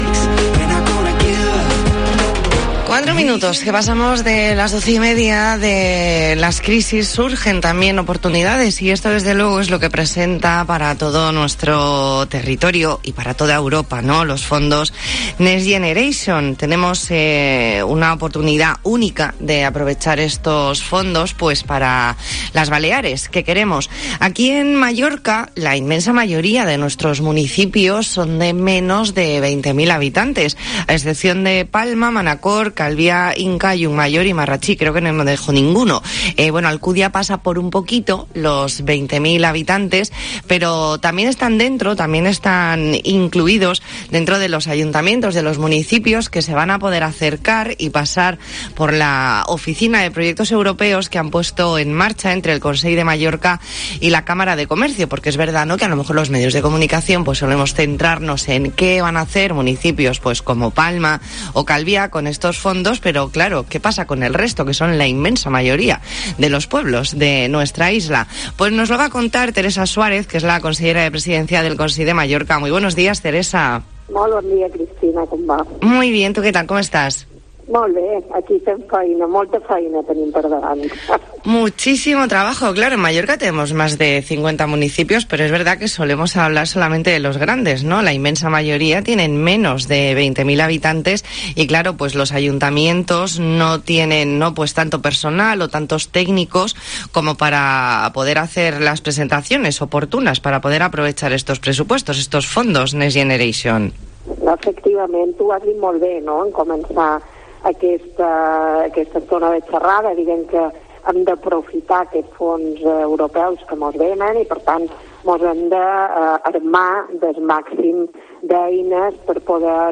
Hablamos con Teresa Suárez, consellera de Presidencia del Consell de Mallorca . Entrevista en La Mañana en COPE Más Mallorca, jueves 14 de octubre de 2021.